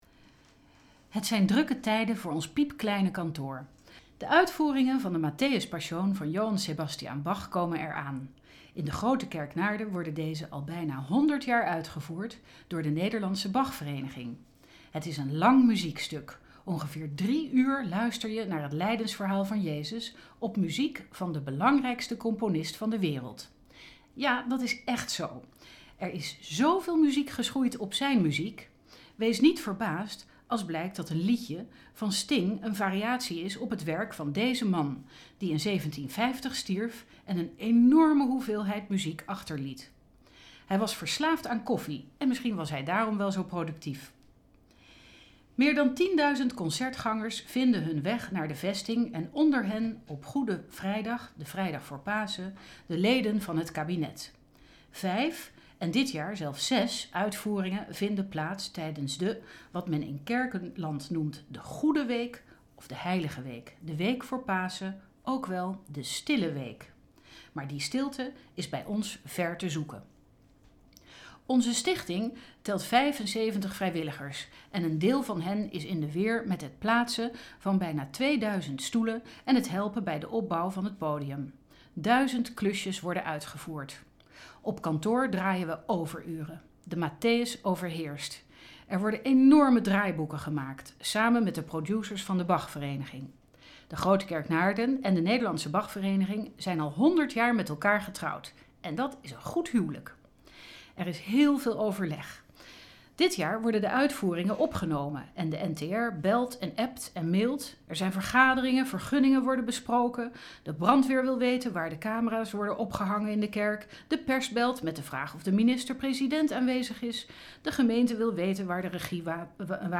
De Gesproken Column van deze week komt uit Naarden.